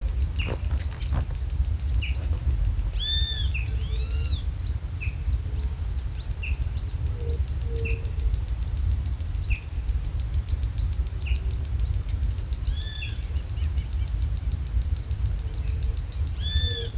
Bate-bico (Phleocryptes melanops)
Nome em Inglês: Wren-like Rushbird
Localidade ou área protegida: Paraná
Condição: Selvagem
Certeza: Observado, Gravado Vocal